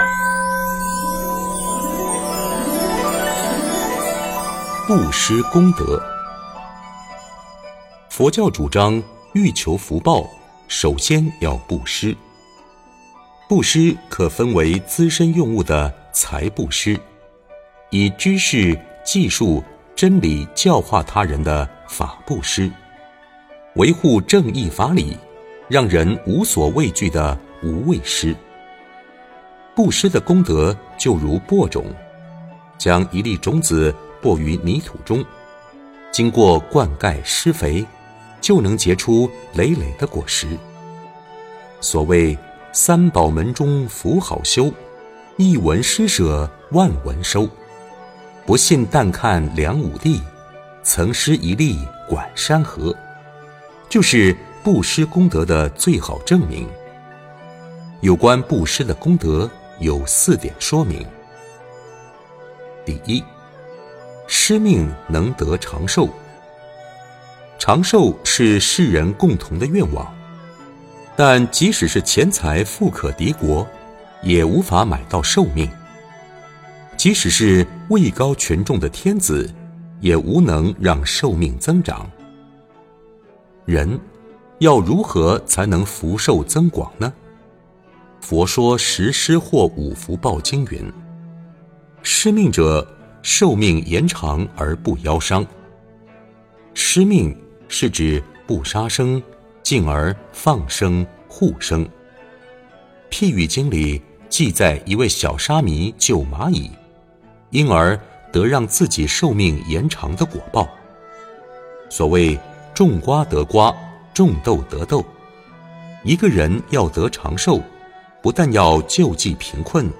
佛音 冥想 佛教音乐 返回列表 上一篇： 11.